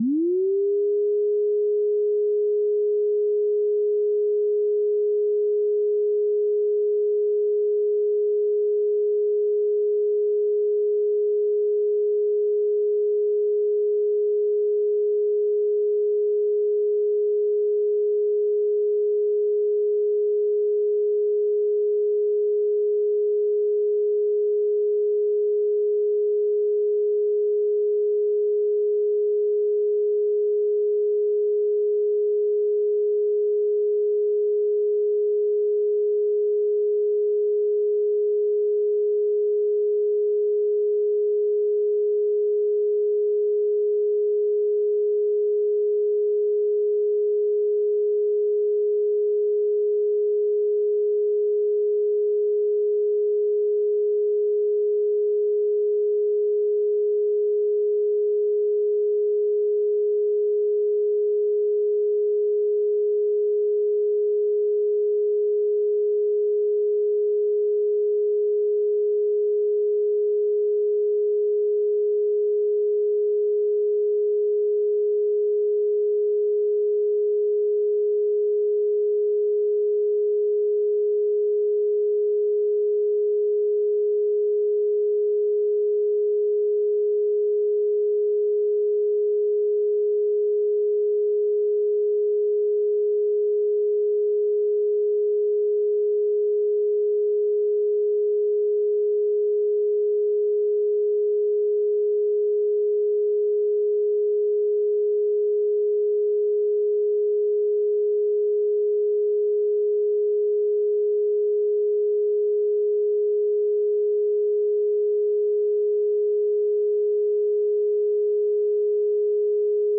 417 Hz Tone Sound Solfeggio Frequency
Solfeggio Frequencies